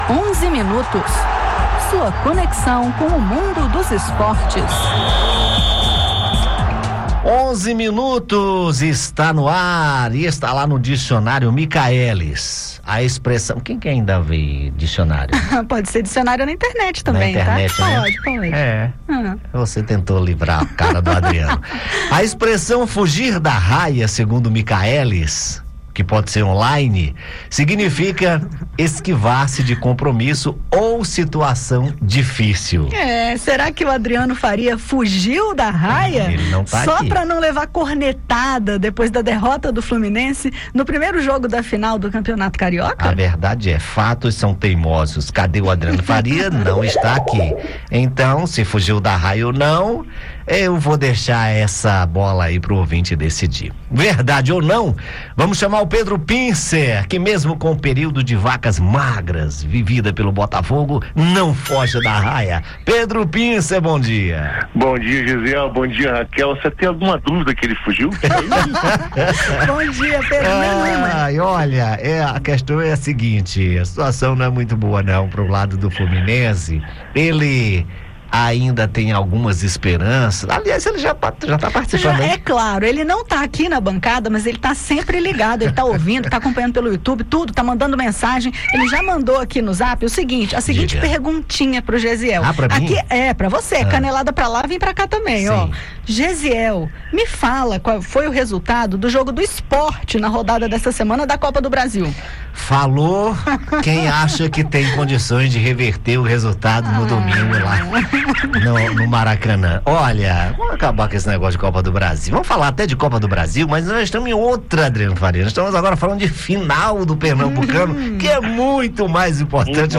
Na parte dos comentários esportivos com o jornalista